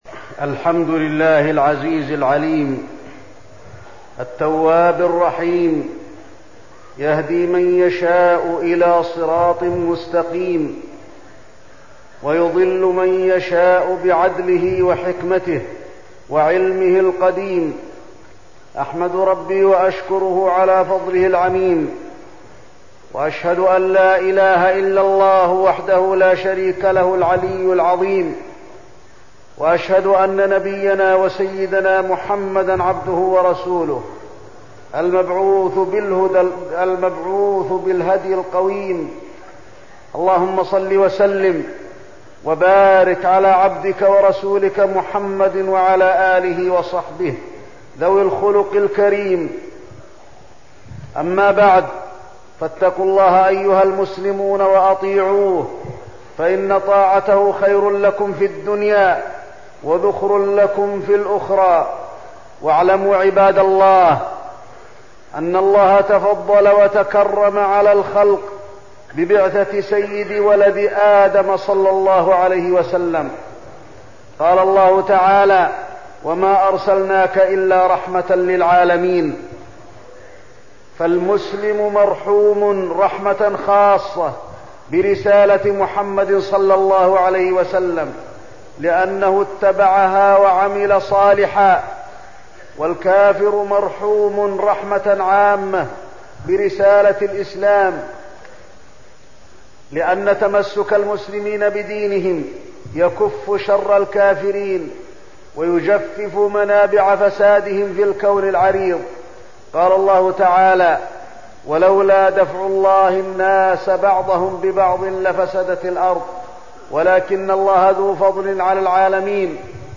تاريخ النشر ٤ محرم ١٤١٦ هـ المكان: المسجد النبوي الشيخ: فضيلة الشيخ د. علي بن عبدالرحمن الحذيفي فضيلة الشيخ د. علي بن عبدالرحمن الحذيفي إتباع السنة والابتعاد عن البدع